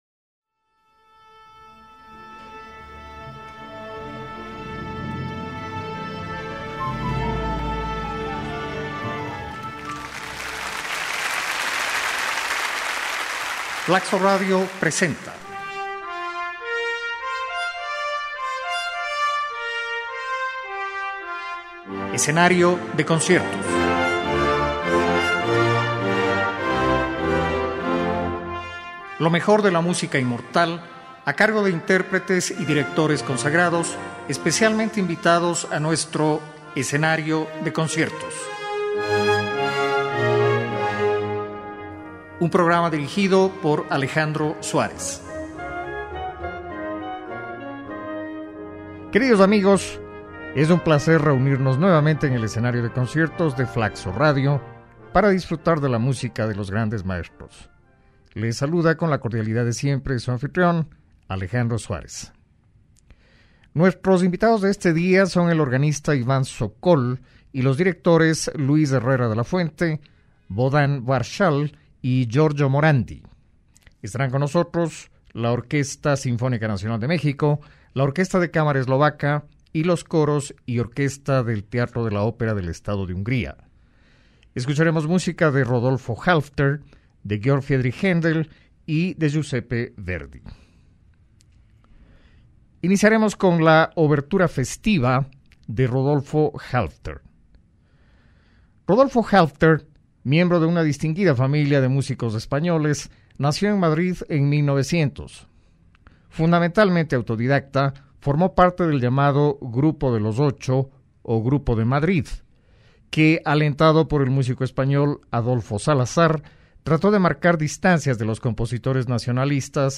Georg Friedrich Handel estará de regreso en el Escenario de Conciertos con uno de sus conciertos para órgano y orquesta.
Orquesta Sinfónica Nacional de México
Orquesta de Cámara Eslovaca